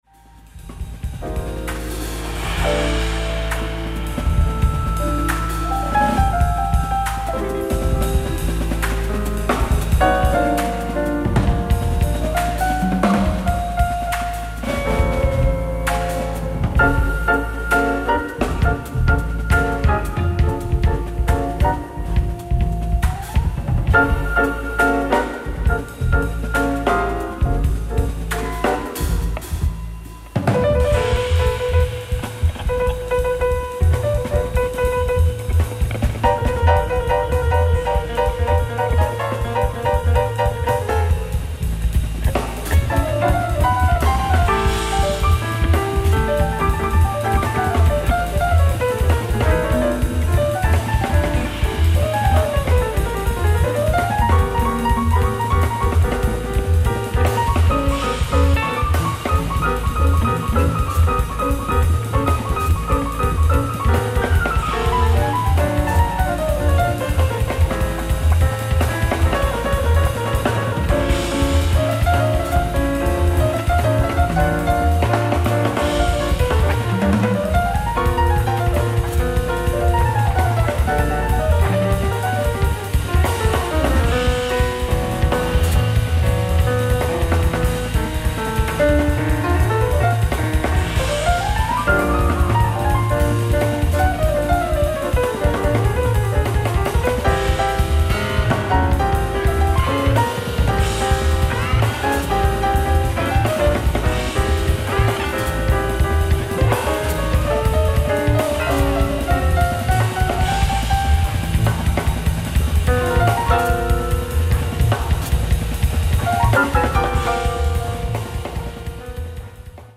ライブ・アット・ビッグトップ、マルシアック、フランス 07/29/2025
放送用音源を収録！！
※試聴用に実際より音質を落としています。